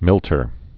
(mĭltər)